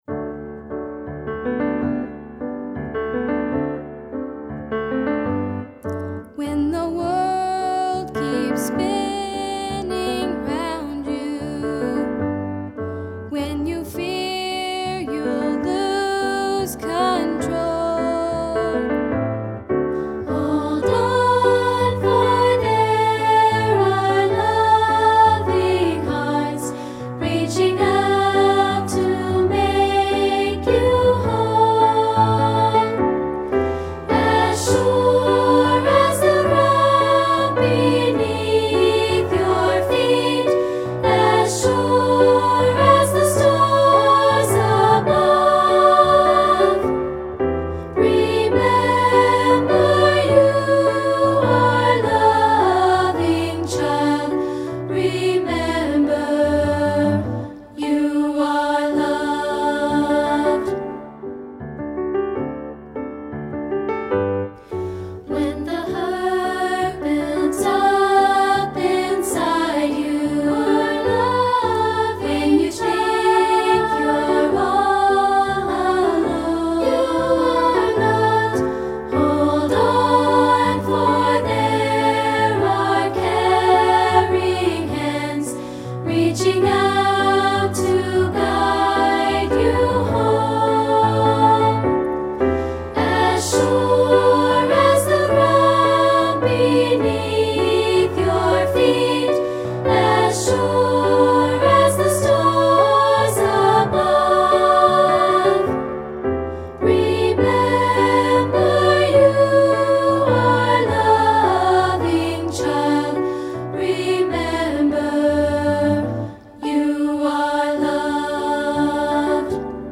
Voicing: Unison/2-Part and Piano